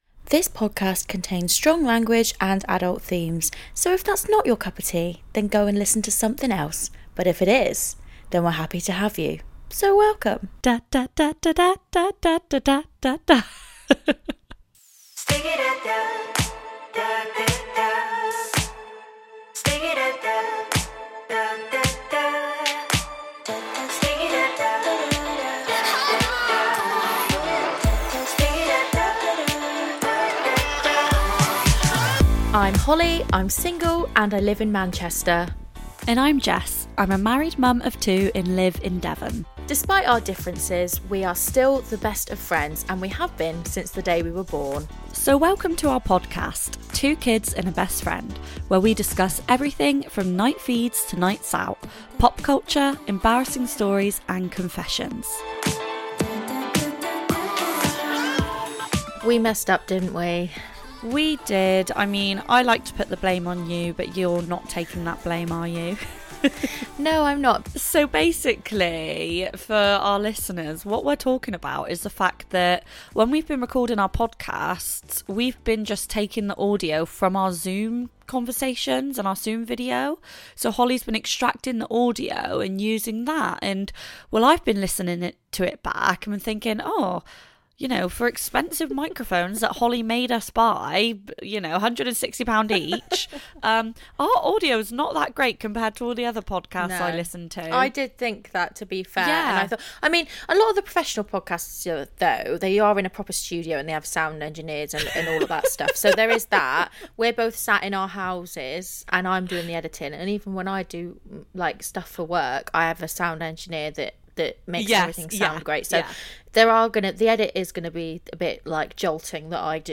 The girls confessed how they've screwed up... BY NOT RECORDING THIER AUDIO PROPERLY!